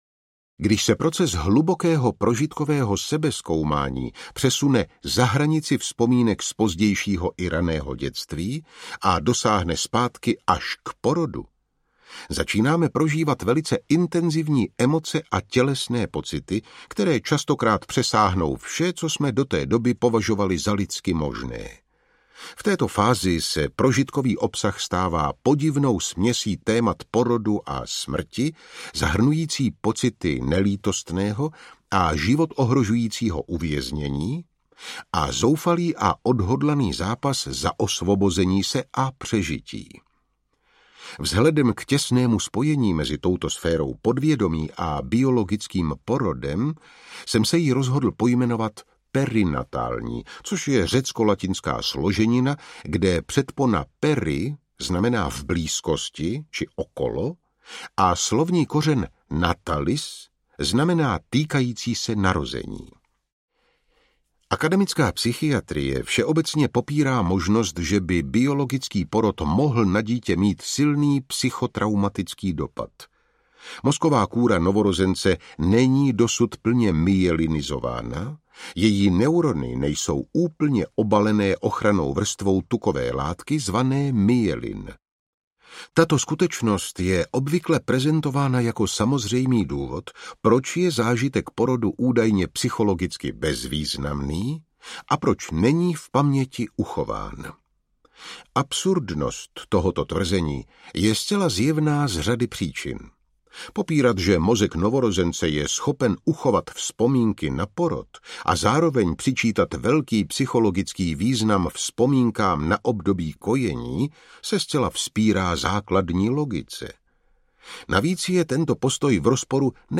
Audiokniha Stanislava Grofa přináší myšlenky, které znamenají revoluční převrat v teoretickém chápání a přístupu ke smrti a umírání. Úvodní kapitoly knihy probírají starověké a domorodé rituální, duchovní praktiky, které pomáhají porozumět zážitkům blízkýmsmrti.
Vypočujte si ukážku audioknihy